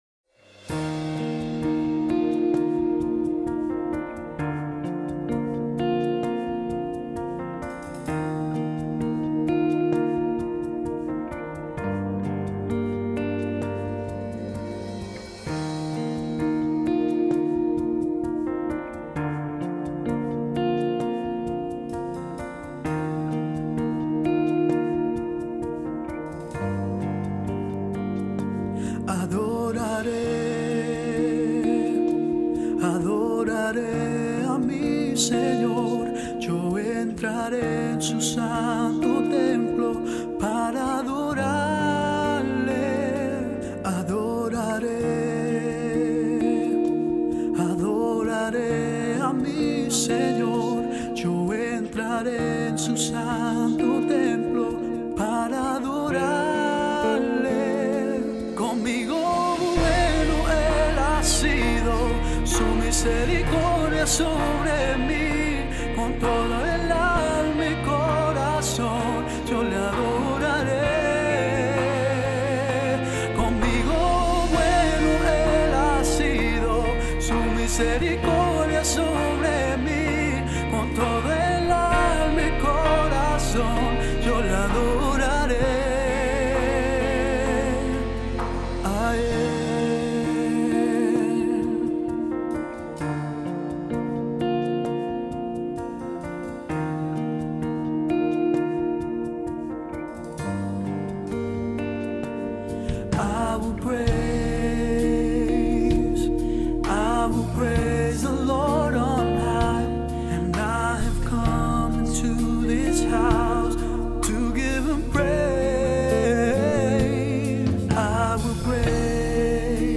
General, Música Cristiana
Alabanza y adoración.